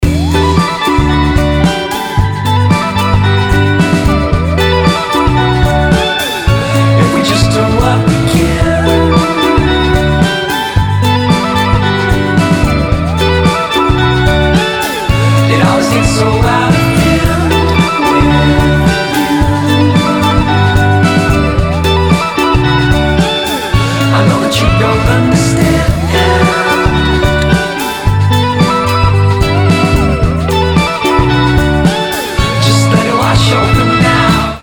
Electronic
indie pop
alternative
dream pop